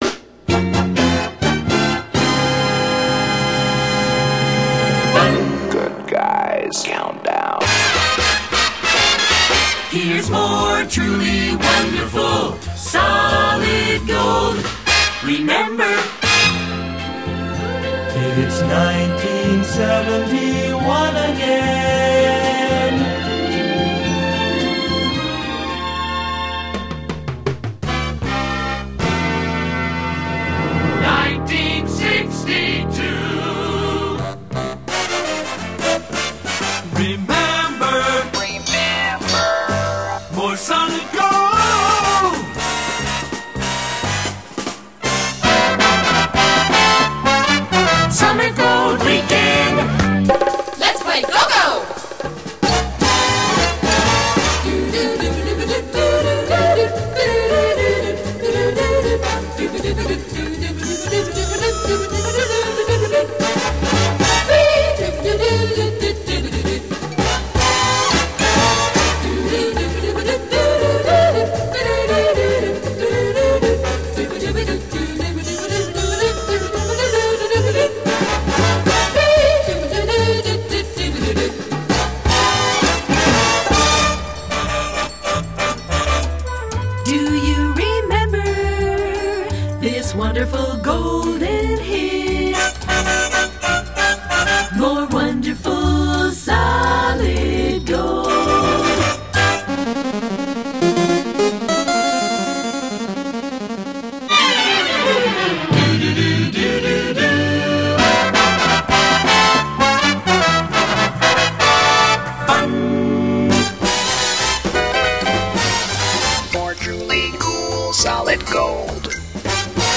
Both use brass.